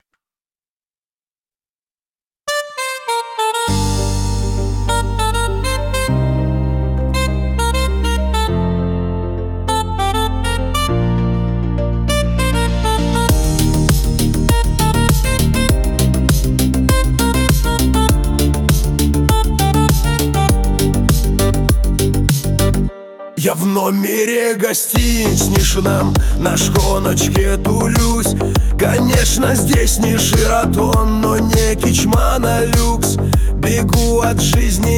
Chanson in Russian